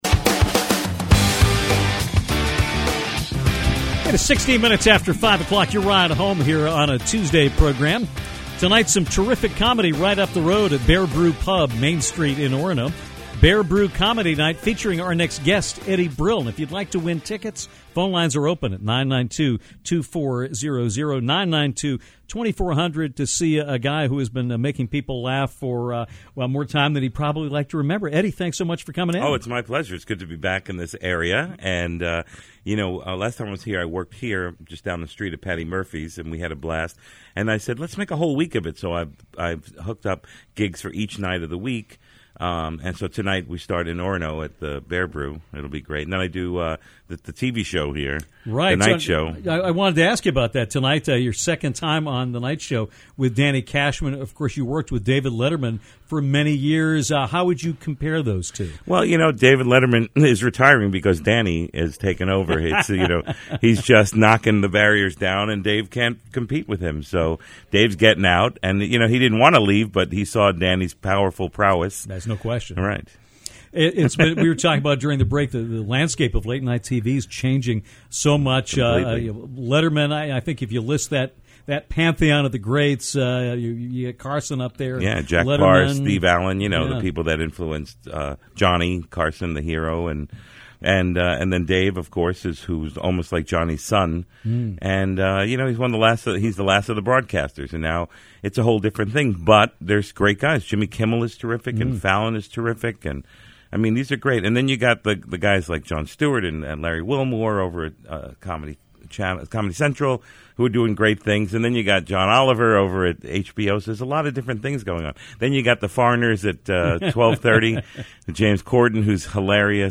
Legendary comedian Eddie Brill came into Downtown’s Seasons restaurant studios to talk about his career in the business and working with the great David Letterman. He also promoted his upcoming appearance on “The Nite Show” on April 8th.